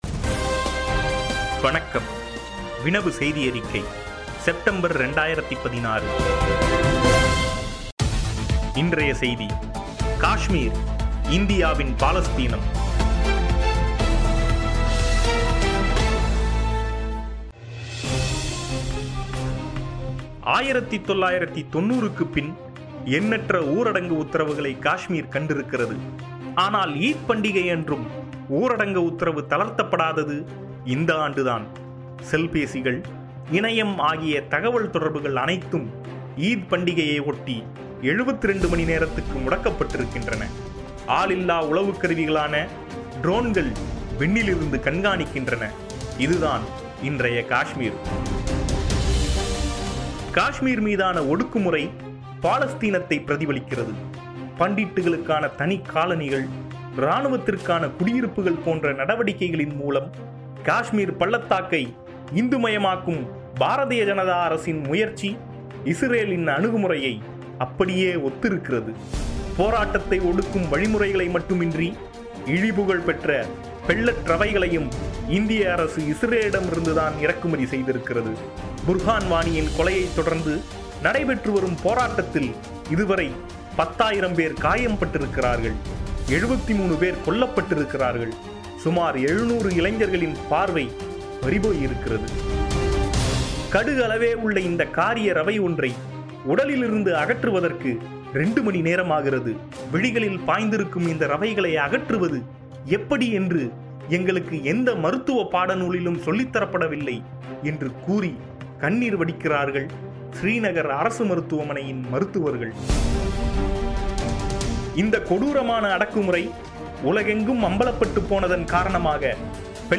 காஷ்மீர் மீதான ஒடுக்குமுறை, பாலஸ்தீனத்தைப் பிரதிபலிக்கிறது. பண்டிட்டுகளுக்கான தனிக் காலனிகள், இராணுவத்தினருக்கான குடியிருப்புகள் போன்ற நடவடிக்கைகளின் மூலம் காஷ்மீர் பள்ளத்தாக்கை இந்துமயமாக்கும் பாரதிய ஜனதா அரசின் முயற்சி இஸ்ரேலின் அணுகுமுறையை அப்படியே ஒத்திருக்கிறது என்பதை கடந்த 2016-ம் ஆண்டின் நிலைமைகளில் இருந்து அச்சமயத்தில் எழுதப்பட்ட கட்டுரையின் கேட்பொலி…